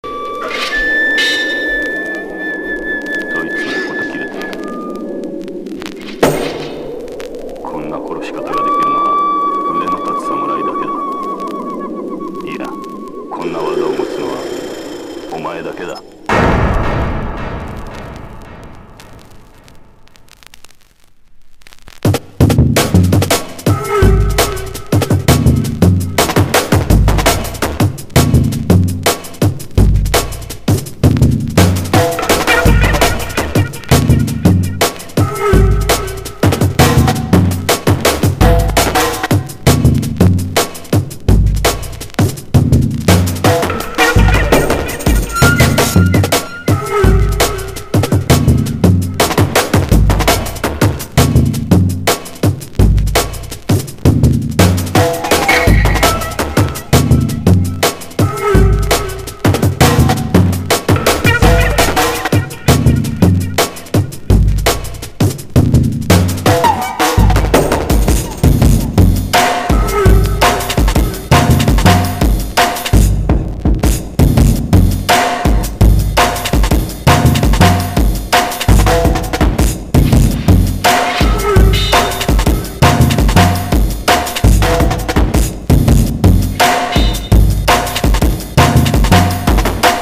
HEADZ / ELECTRONICA / CHILOUT# DUB / LEFTFIELD